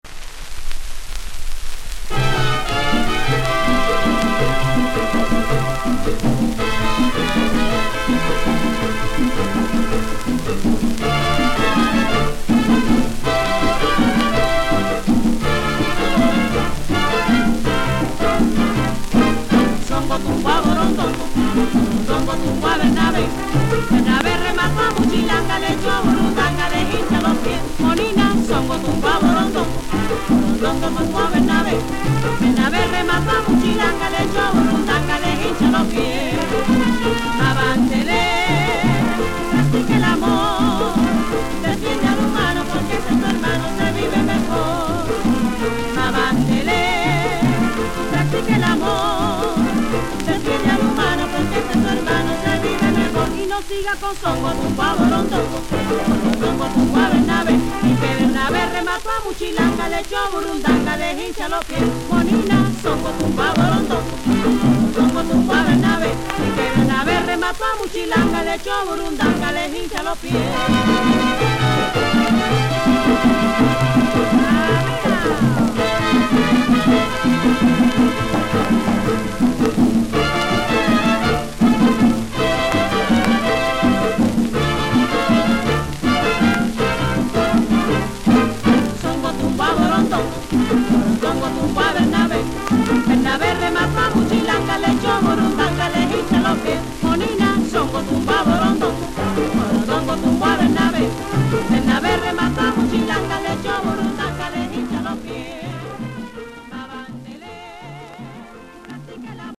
本商品は10inch SP盤78回転です。